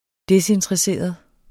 Udtale [ ˈdesentʁəˌseˀʌð ]